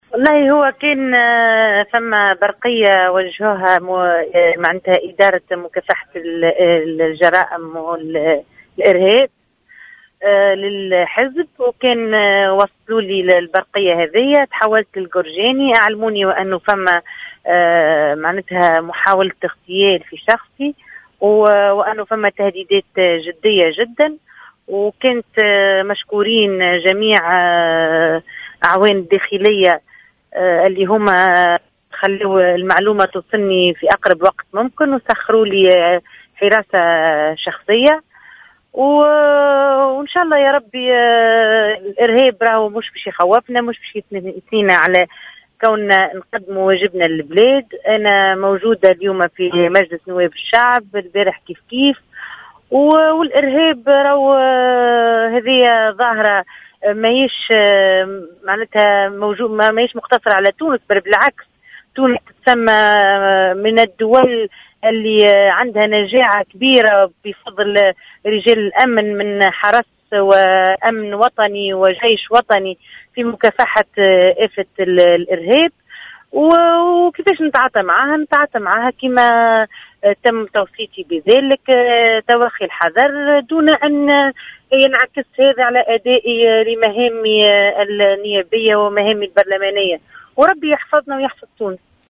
أكدت القيادية في حركة نداء تونس والنائبة في مجلس نواب الشعب، أنس الحطاب في تصريح هاتفي للجوهرة "اف ام"، اليوم الجمعة أن الحزب تلقى برقية من فرقة مكافحة الإرهاب، بخصوص تهديد باغتيالها، حيث توجهت إلى مقر الفرقة في القرجاني أين أبلغها الأعوان بجدية هذه التهديدات.